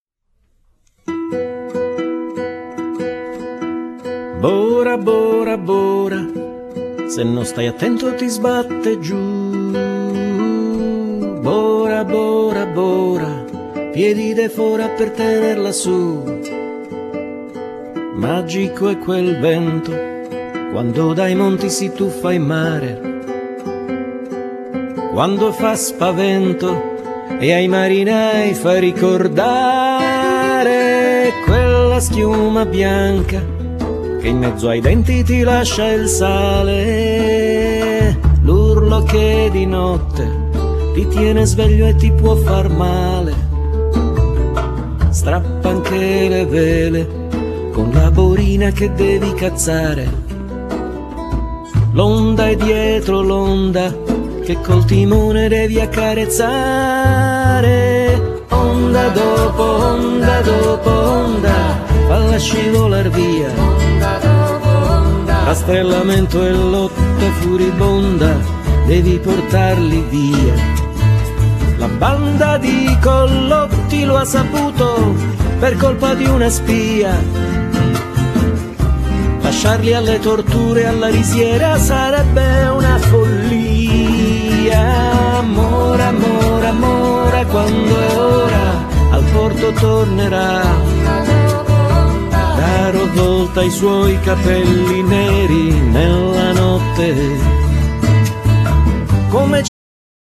Genere : Pop
un brano sognante, e al tempo stesso movimentato